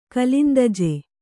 ♪ gōlandāju ilākhe